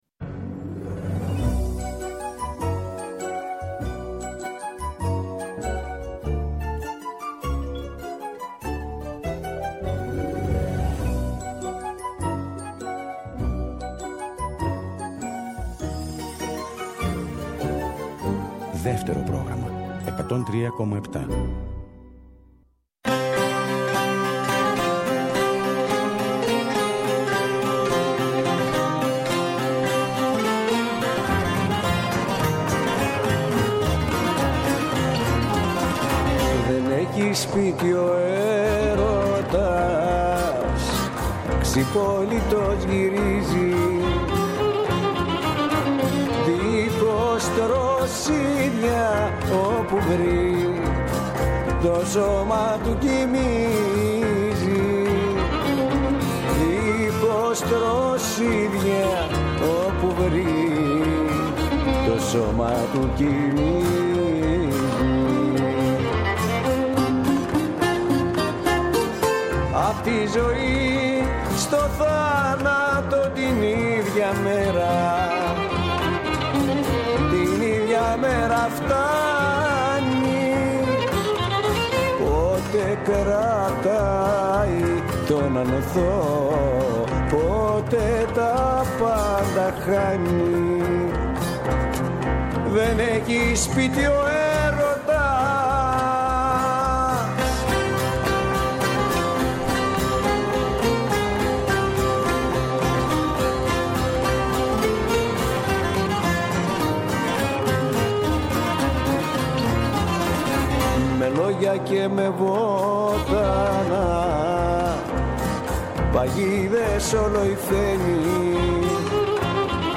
ραδιοφωνικό – μουσικό road trip ΔΕΥΤΕΡΟ ΠΡΟΓΡΑΜΜΑ